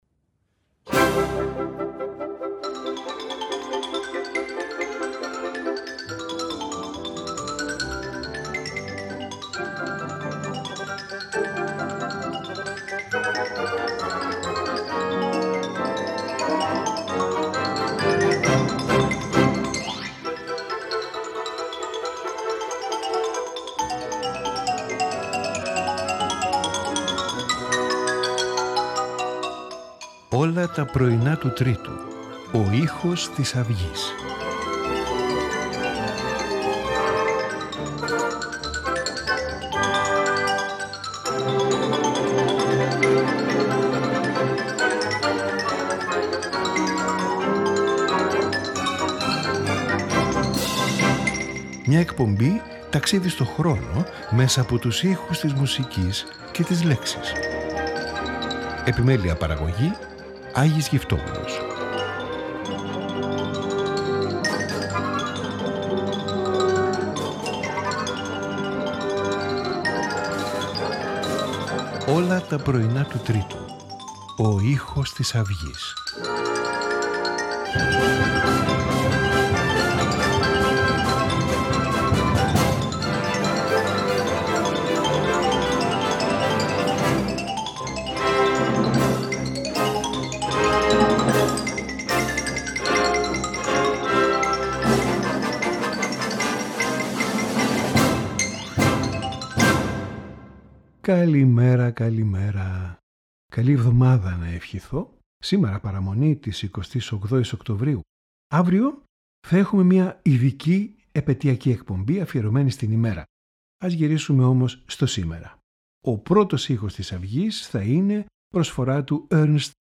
String Quartet in E-flat major
Cello Sonata in E minor
Sonata in E major